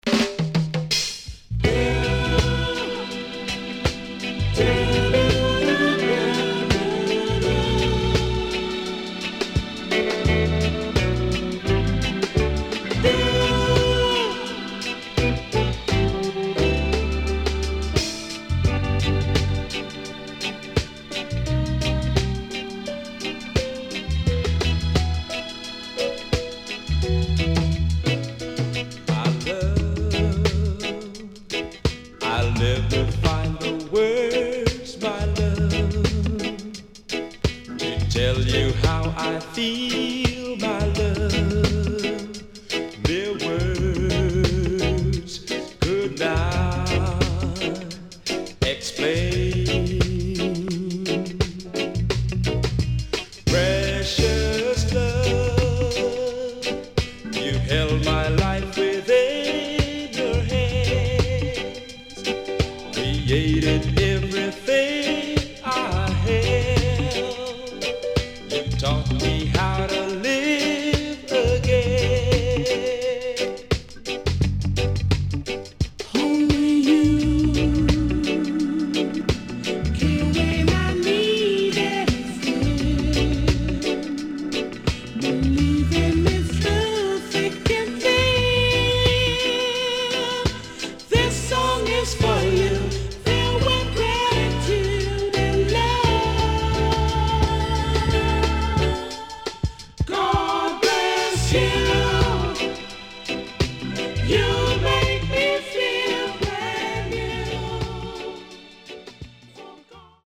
SIDE A:少しチリノイズ入りますが良好です。
SIDE B:少しチリノイズ入りますが良好です。